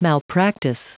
Prononciation du mot malpractice en anglais (fichier audio)
Prononciation du mot : malpractice